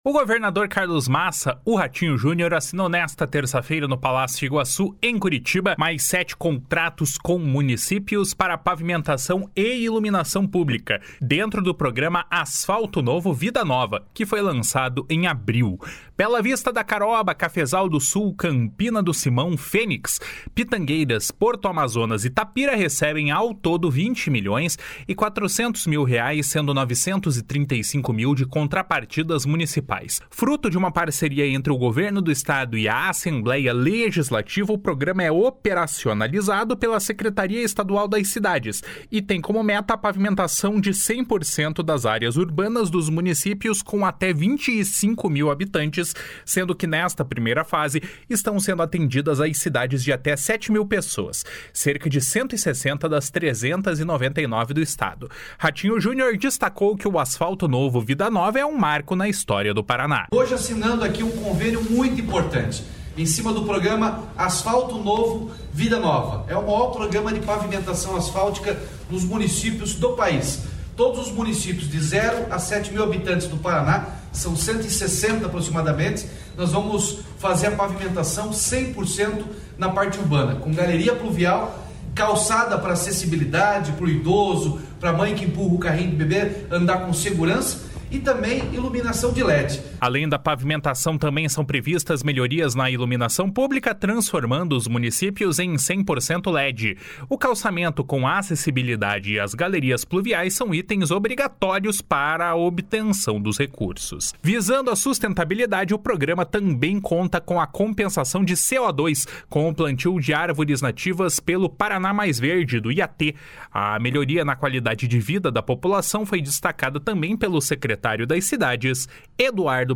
Ratinho Junior destacou que o Asfalto Novo, Vida Nova é um marco na história do Paraná. // SONORA RATINHO JUNIOR //